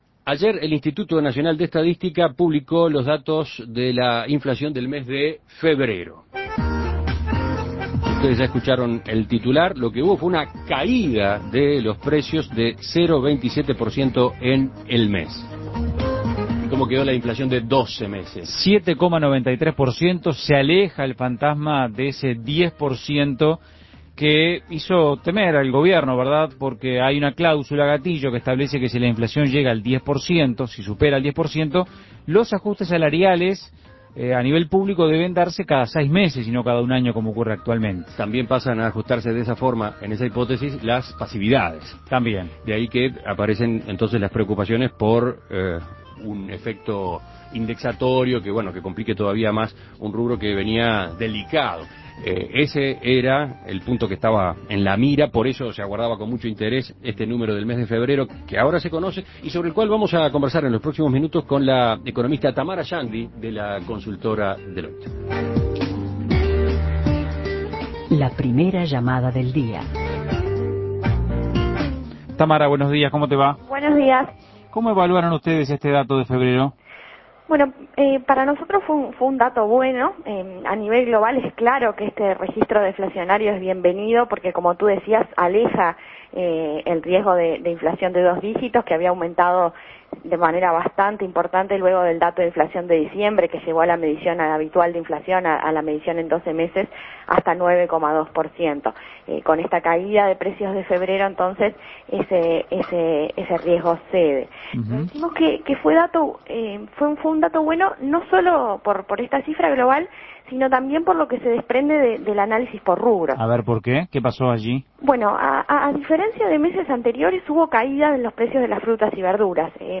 Análisis Económico El IPC marcó deflación en febrero.